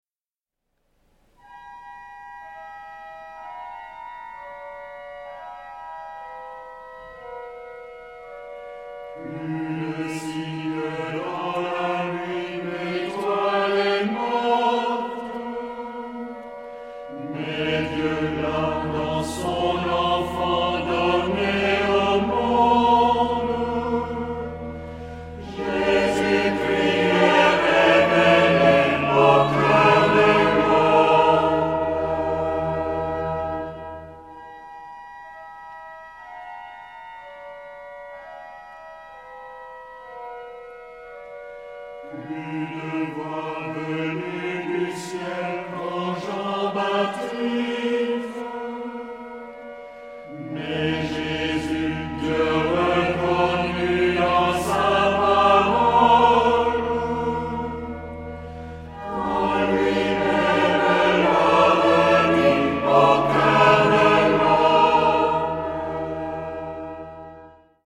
Genre-Style-Form: hymn (secular)
Mood of the piece: meditative
Type of Choir: SATB  (4 mixed + congregation voices )
Instrumentation: Organ  (1 instrumental part(s))
Tonality: E major